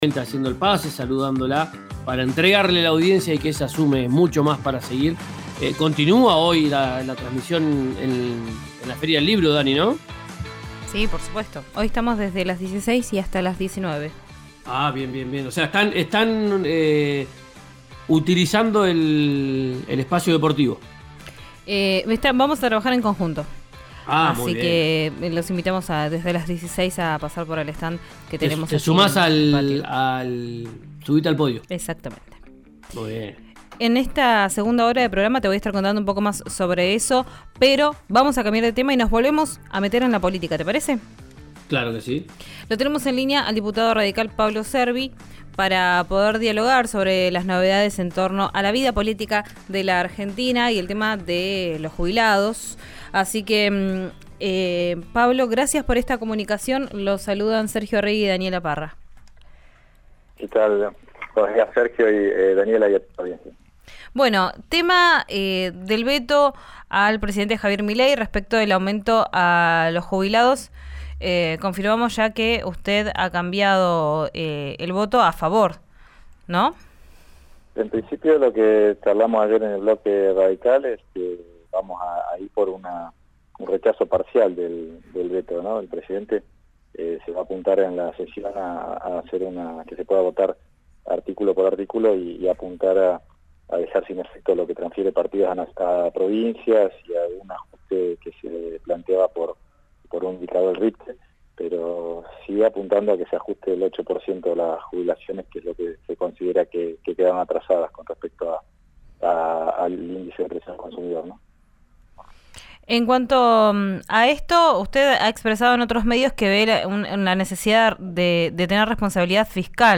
Escuchá a Pablo Cervi, diputado de la UCR, por RÍO NEGRO RADIO:
Tras la reunión que compartió ayer con un grupo de legisladores en Casa Rosada, el radical defendió el cambio de postura en diálogo con RÍO NEGRO RADIO.